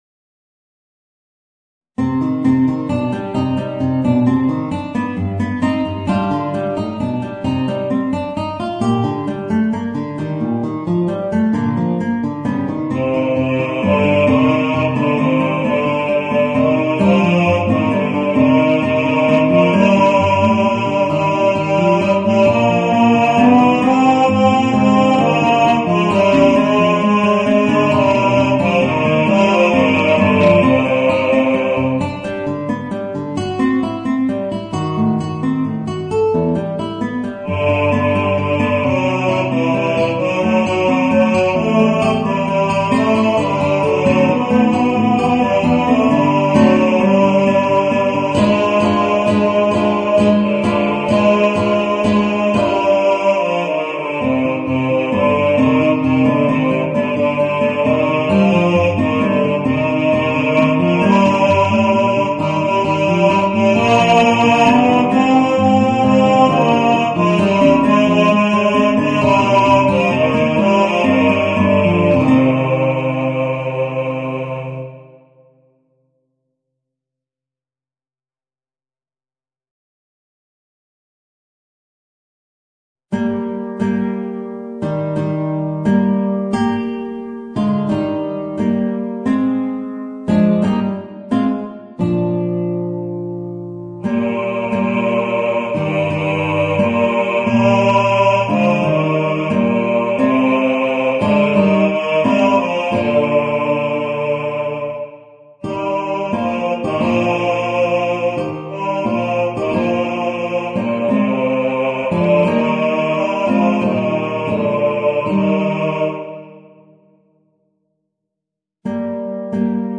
Voicing: Guitar and Bass